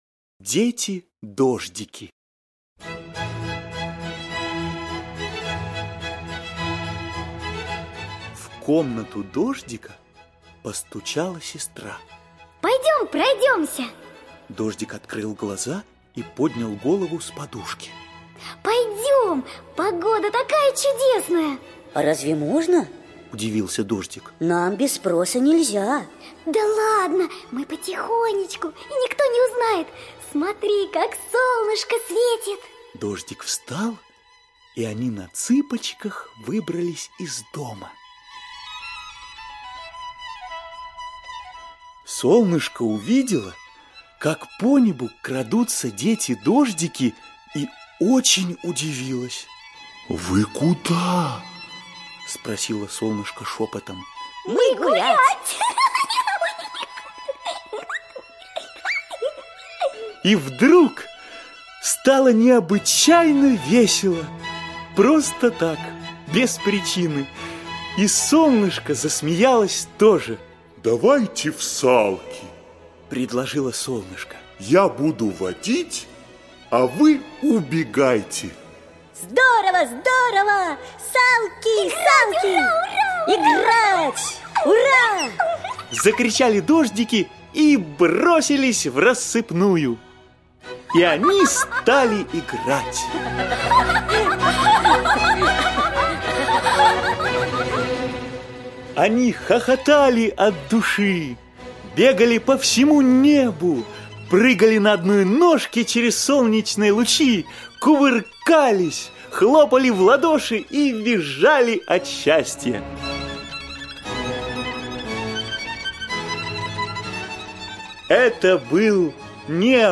Слушайте Дети-дождики - аудиосказка Онисимовой О. В комнату дождика постучала сестра.